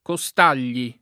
[ ko S t # l’l’i ]